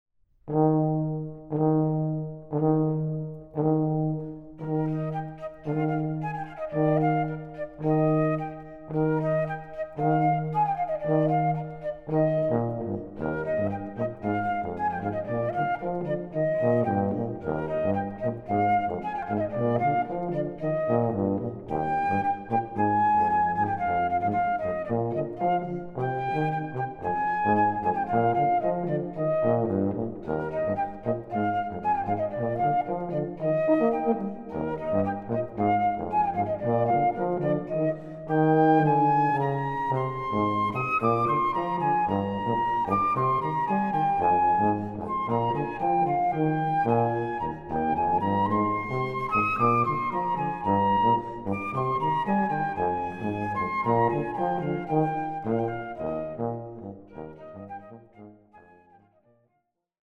Version for Euphonium and Piano